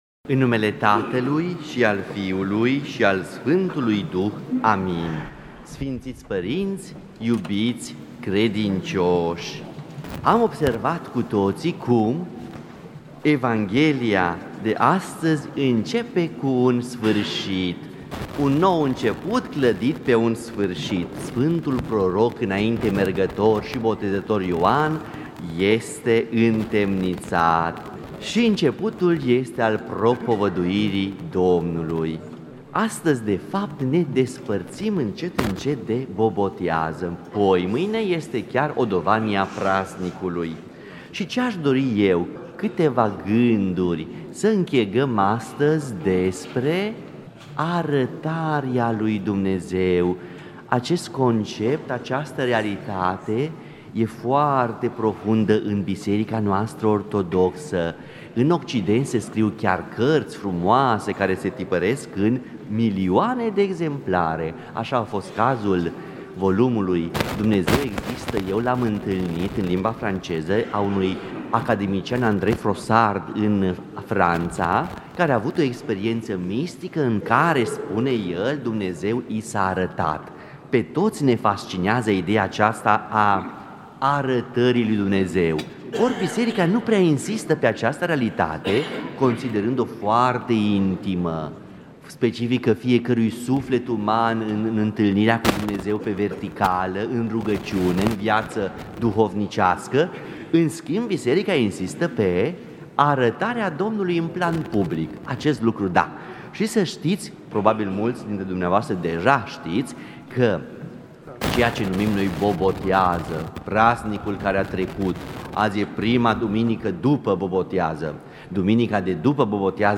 Predică la Duminica după Botezul Domnului